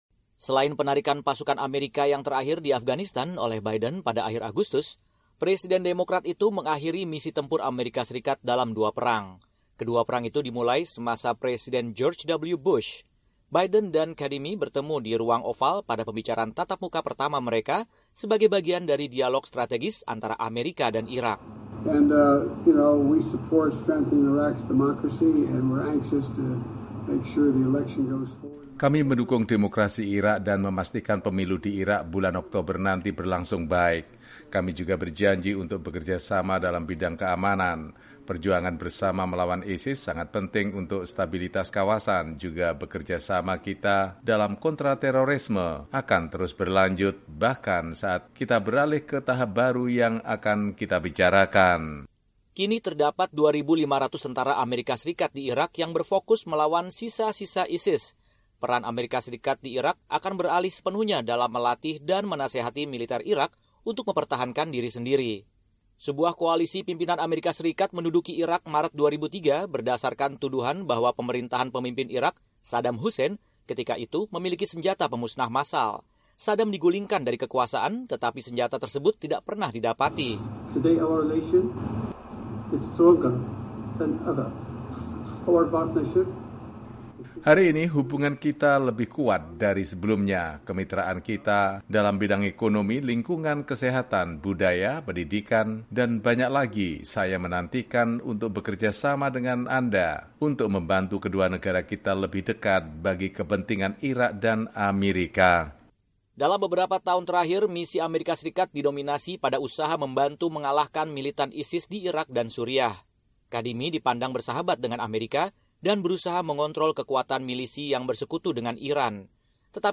laporan